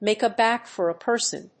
アクセントmàke a báck for a person